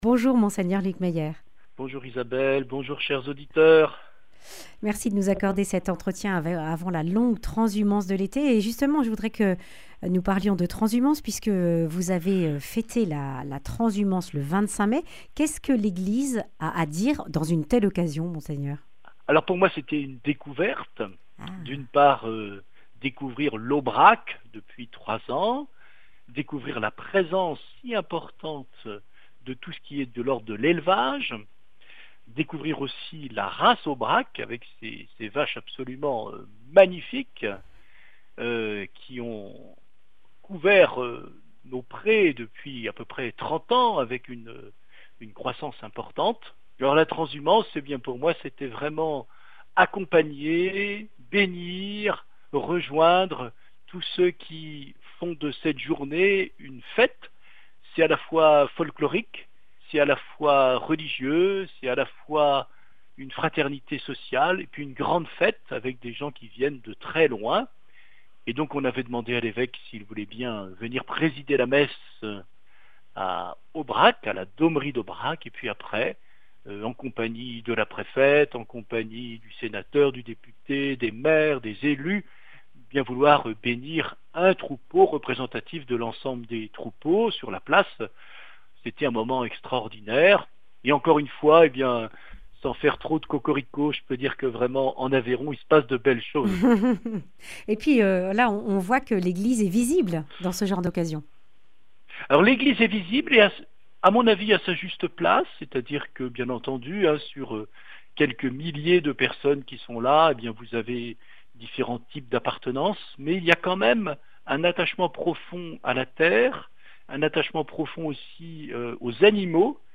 Partager Copier ce code (Ctrl+C) pour l'intégrer dans votre page : Commander sur CD Une émission présentée par Mgr Luc Meyer Evêque du diocèse de Rodez et Vabres Voir la grille des programmes Nous contacter Réagir à cette émission Cliquez ici Qui êtes-vous ?